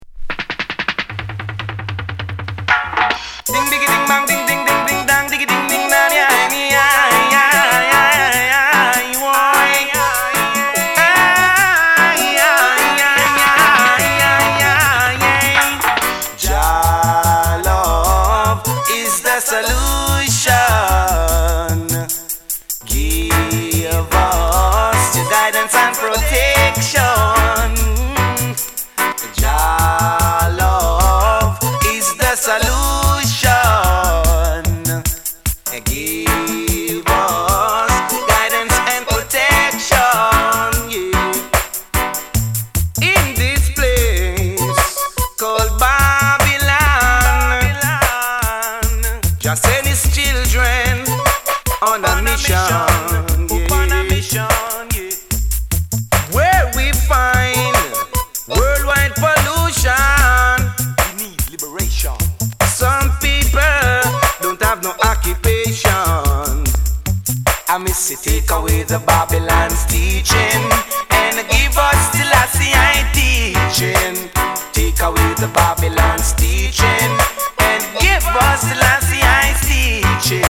Genre: Reggae / Dancehall